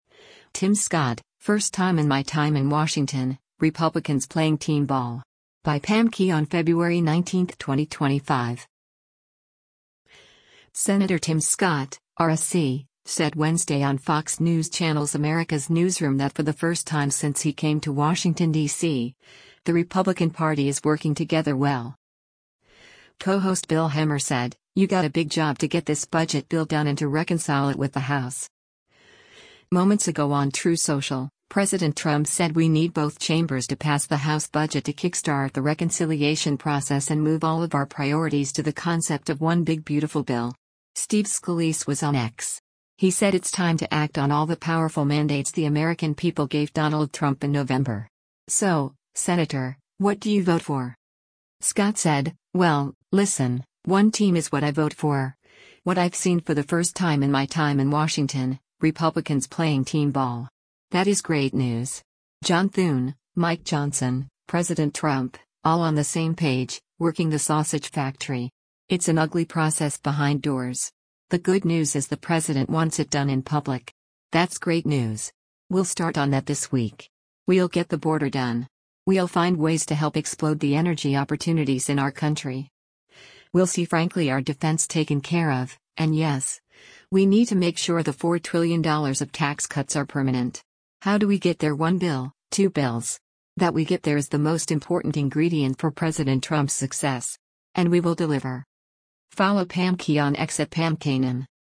Senator Tim Scott (R-SC) said Wednesday on Fox News Channel’s “America’s Newsroom” that for the first time since he came to Washington, D.C., the Republican Party is working together well.